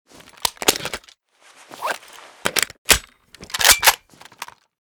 bizon_reload_empty.ogg